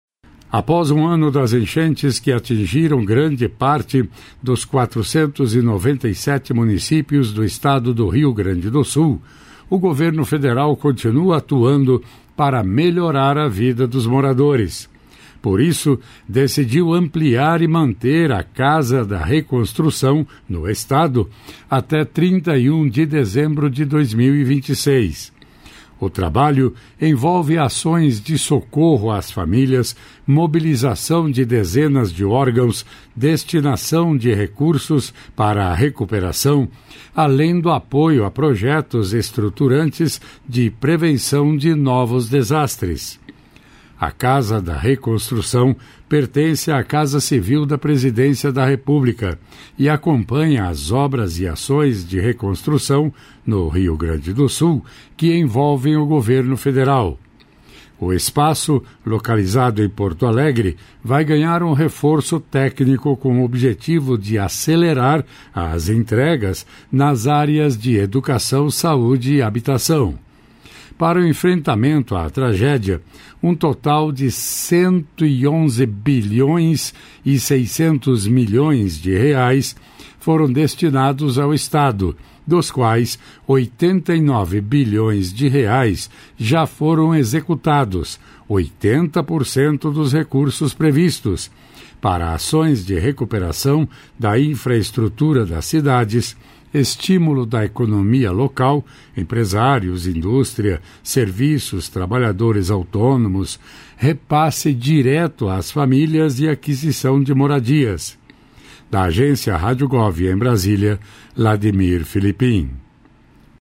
É Notícia